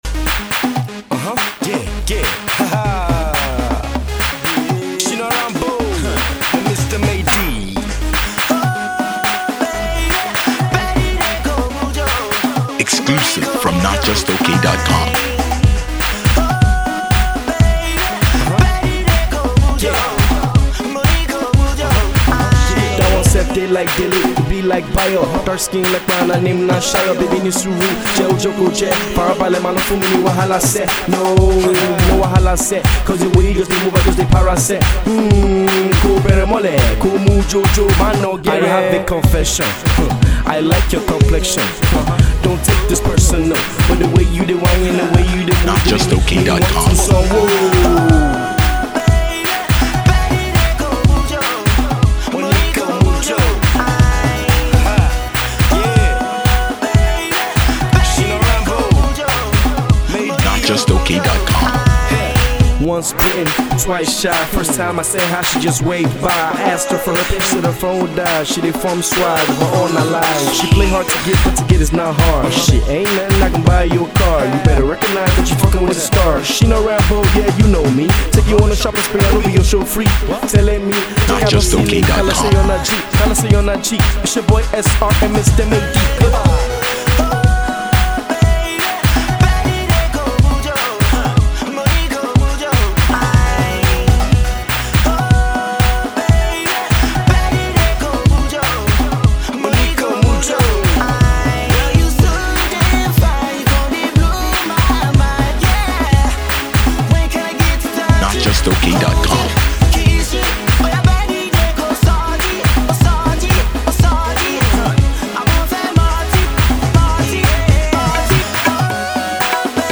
*Note that the song is not fully mixed and mastered.